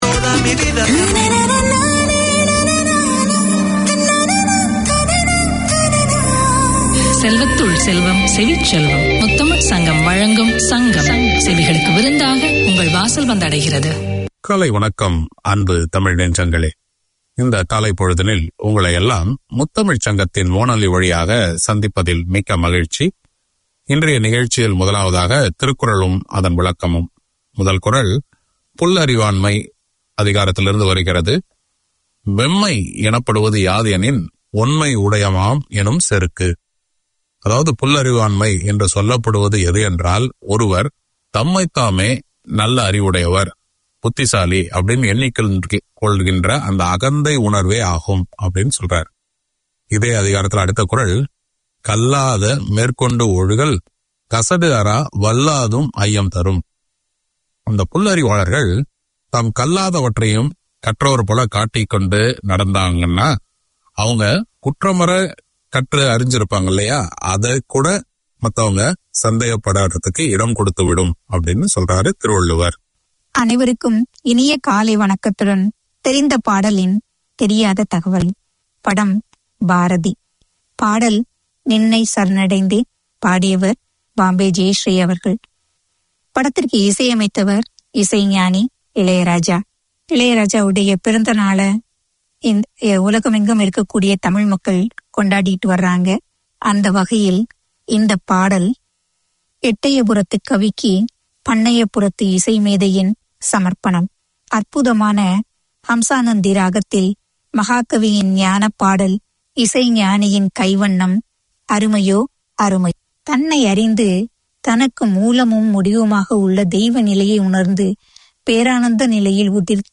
Tamil literature, poems, news and interviews - Melisai brings you current affairs, local and international events relevant to Tamils, with wit and humour. Each Sunday morning there’s the chance to hear local Tamil perspectives presented by Tamil speakers with a passion for the language and culture. The music is varied, the topics entertaining.